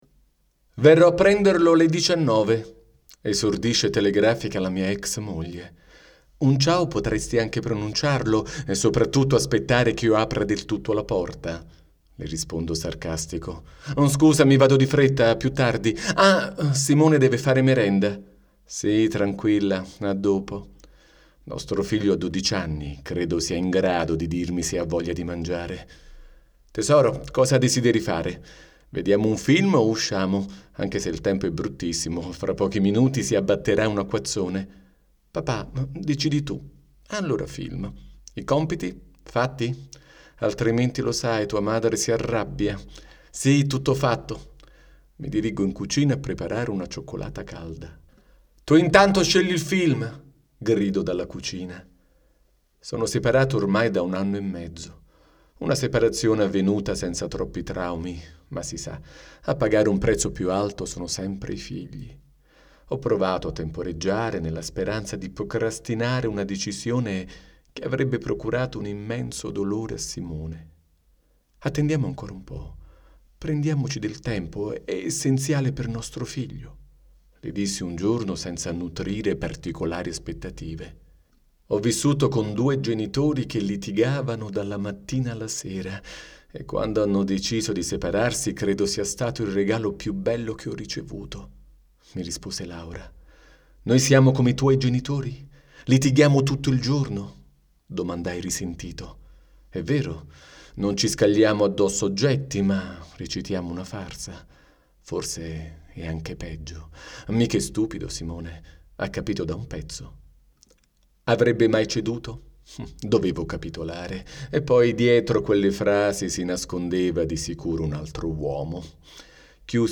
Un podcast dove parla un padre separato alle prese con suo figlio e le sue paure di sentirsi escluso dalla sua nuova vita